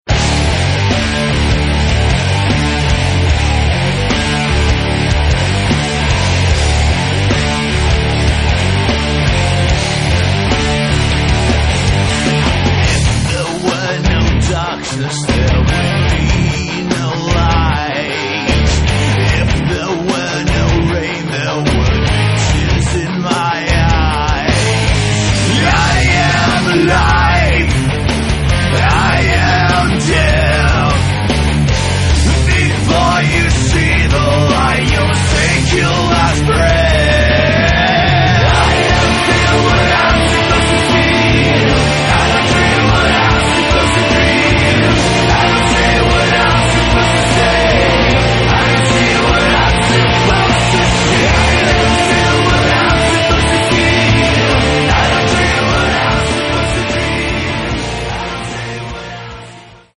Рок
Более рок-н-ролльный дух, настроение шоу.